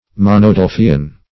Meaning of monodelphian. monodelphian synonyms, pronunciation, spelling and more from Free Dictionary.
Search Result for " monodelphian" : The Collaborative International Dictionary of English v.0.48: Monodelph \Mon"o*delph\, Monodelphian \Mon`o*del"phi*an\, n. (Zool.) One of the Monodelphia.